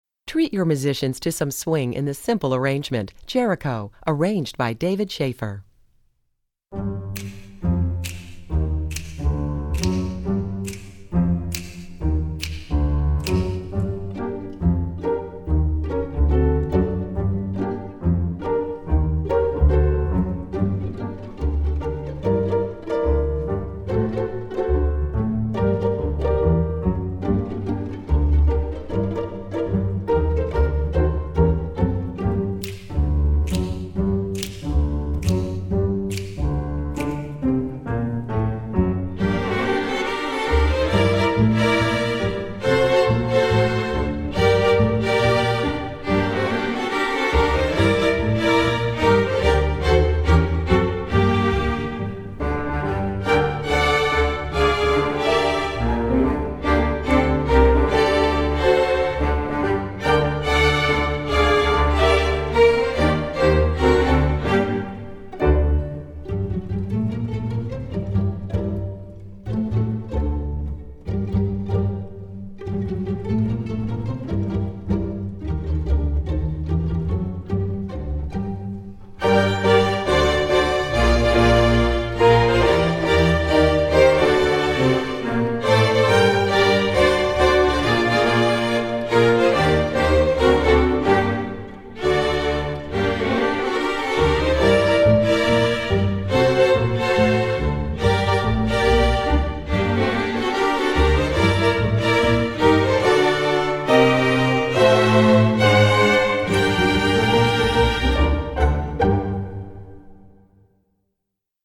Composer: Spiritual
Voicing: String Orchestra